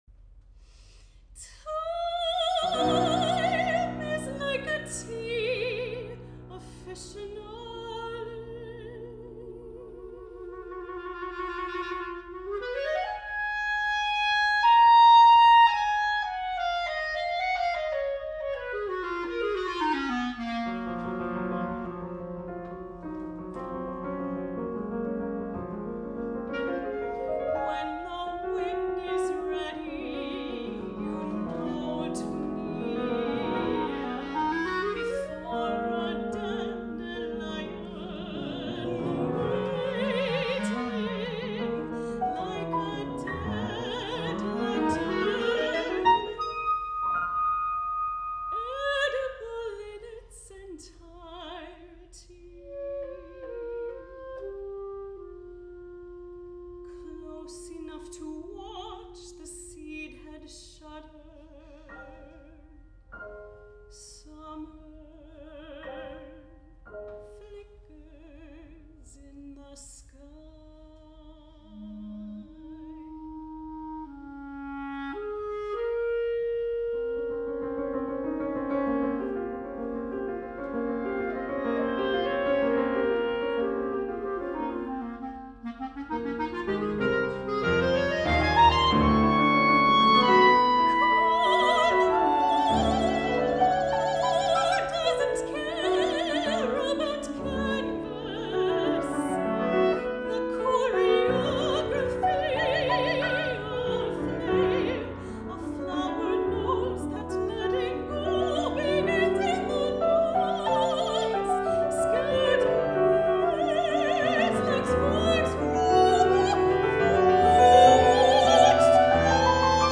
Chamber Music , Individual Art Songs
Soprano